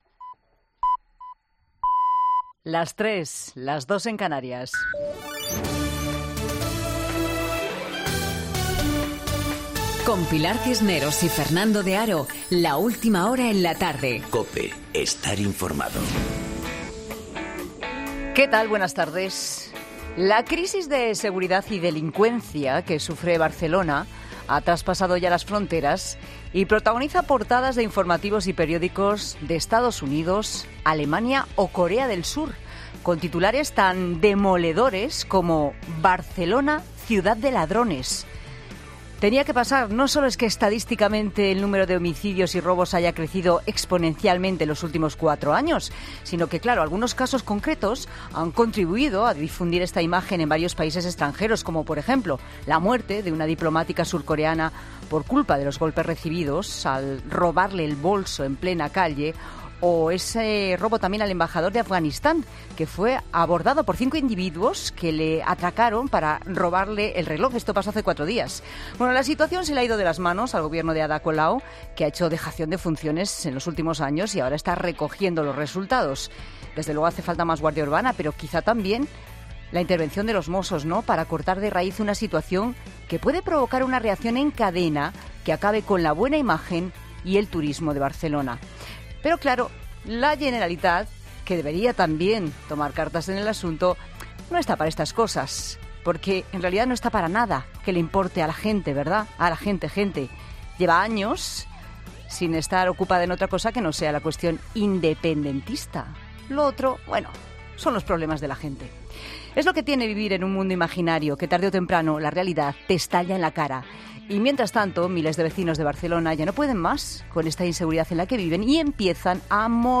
Monólogo de Pilar Cisneros